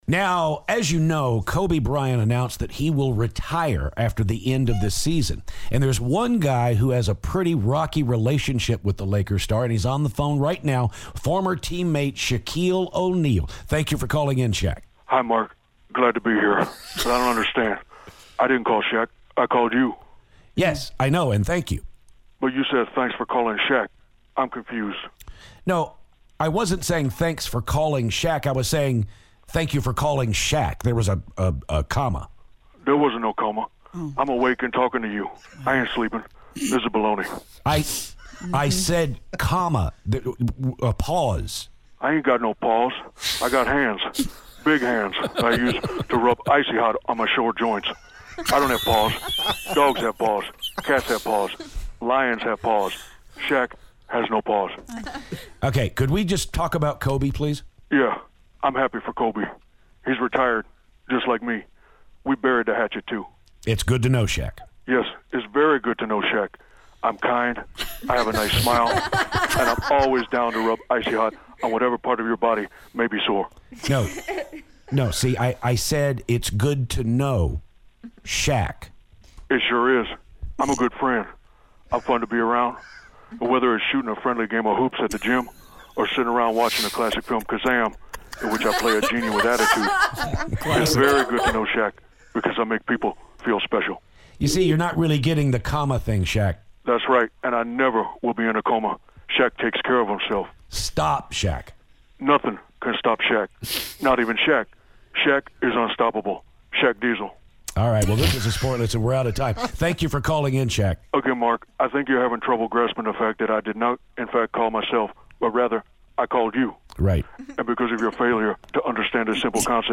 Shaquille O"Neal calls to talk about Kobe's retirement announcement.